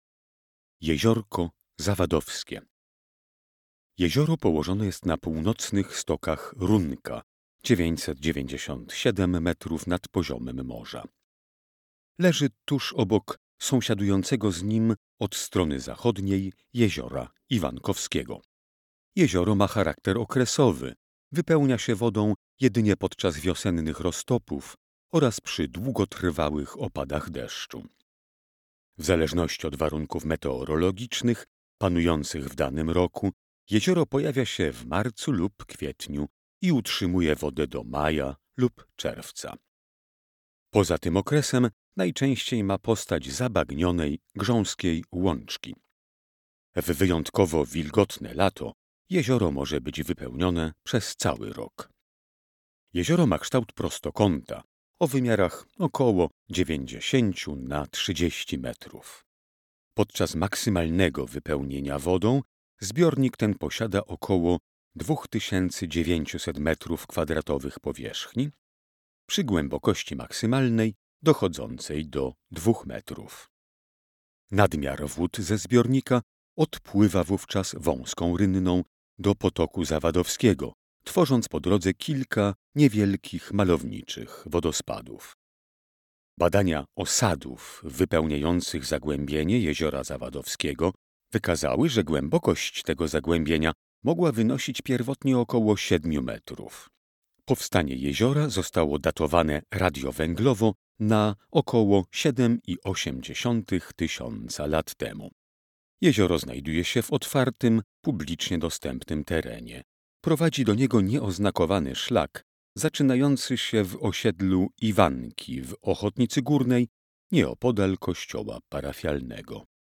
Opis miejsca w wersji audio